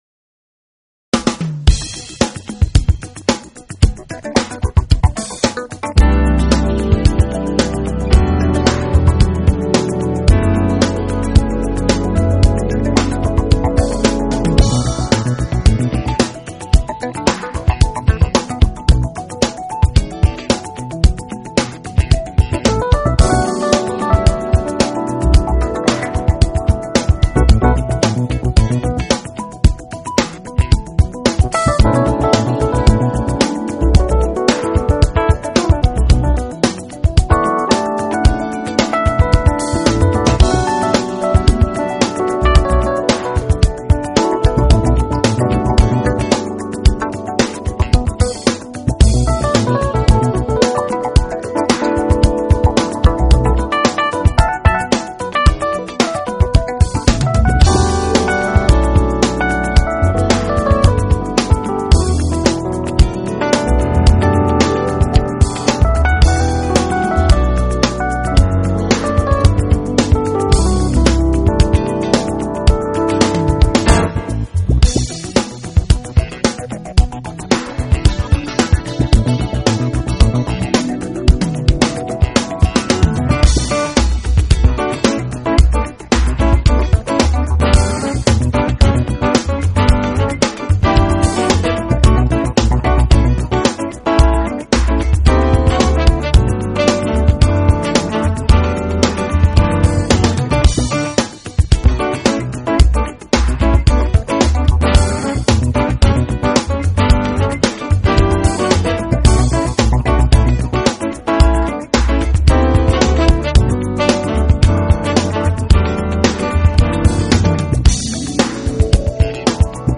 Genre:Jazz, Smooth Jazz